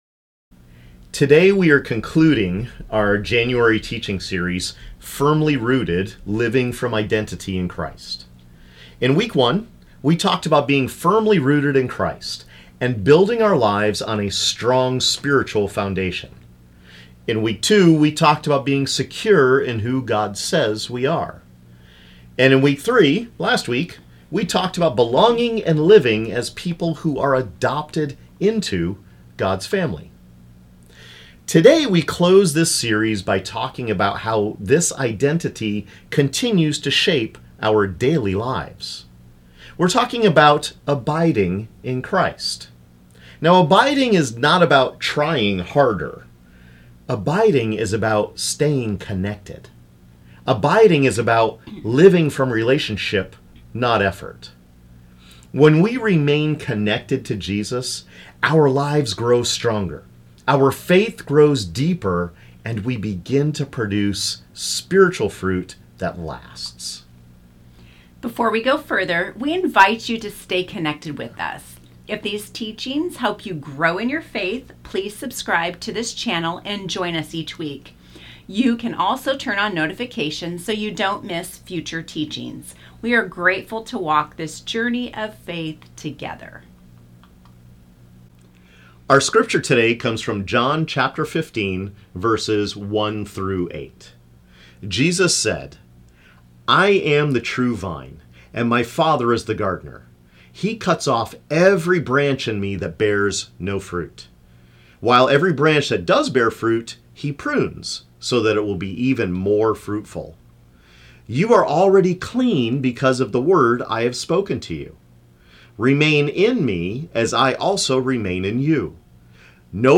This teaching explains what it means to abide in Christ and remain connected to Jesus each day.